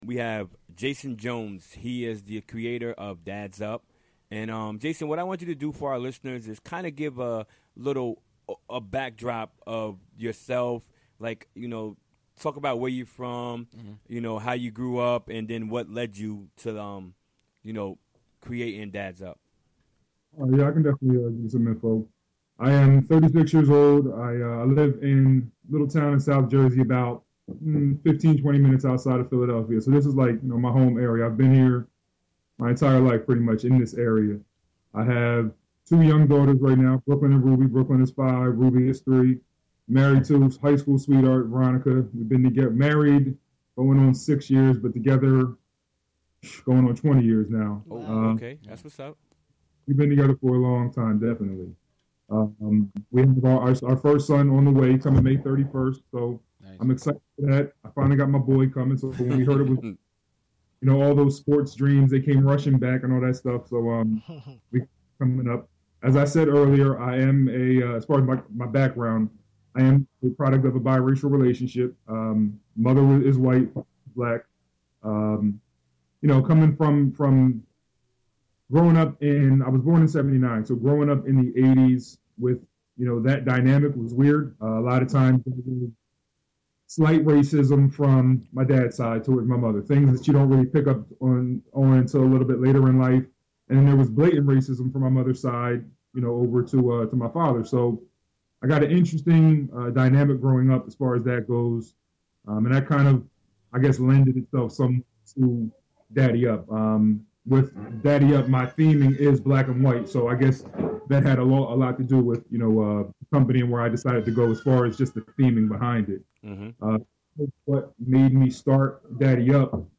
Interview from the WGXC Afternoon Show May 4th.